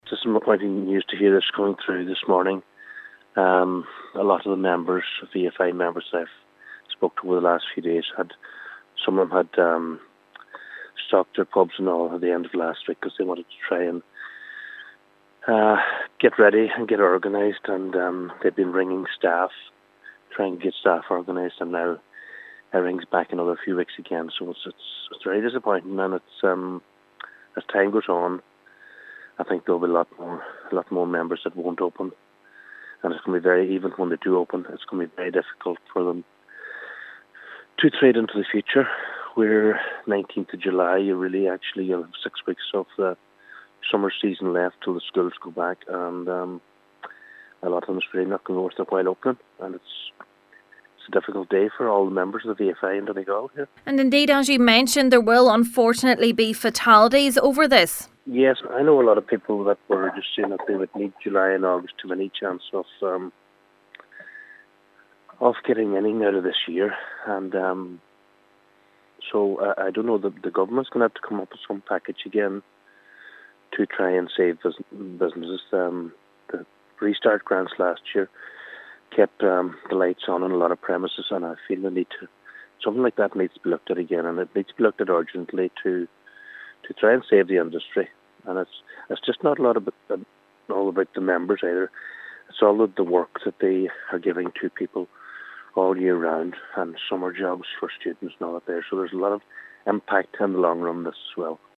Treasurer of the Donegal branch of the VFI Councillor Martin Harley, says a lot of businesses may not reopen after this: